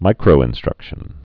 (mīkrō-ĭn-strŭkshən)